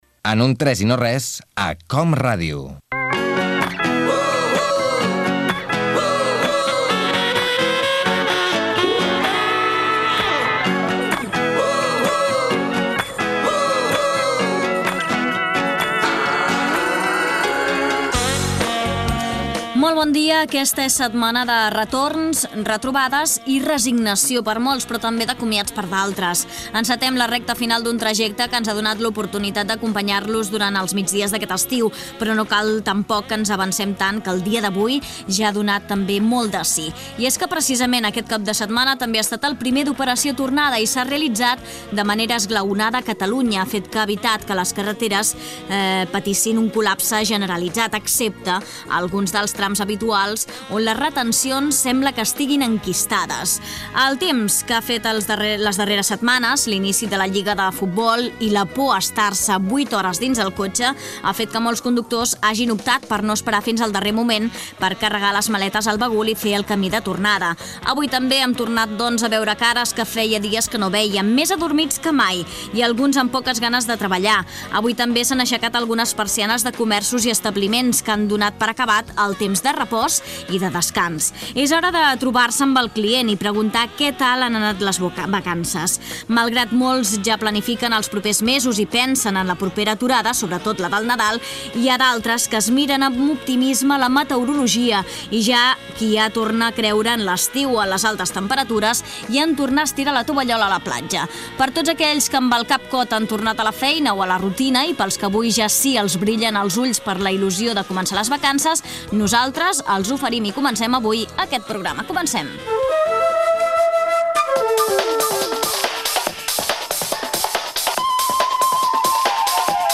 Entreteniment
Fragment extret de l'arxiu sonor de COM Ràdio